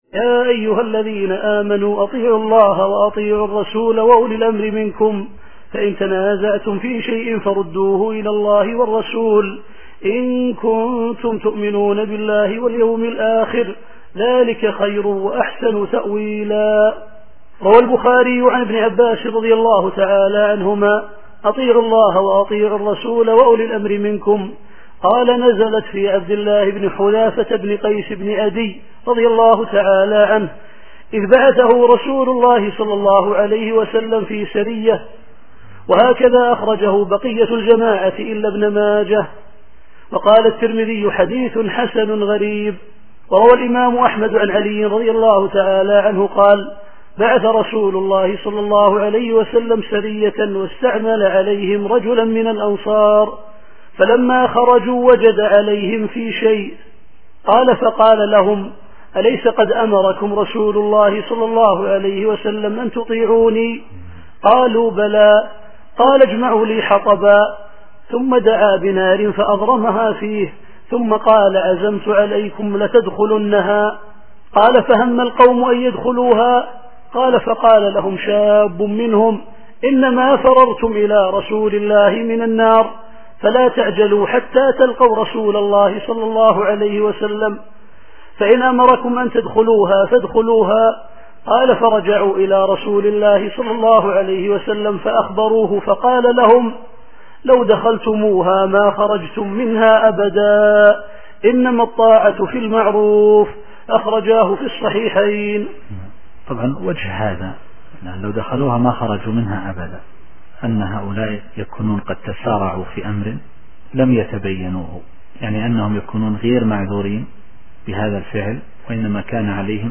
التفسير الصوتي [النساء / 59]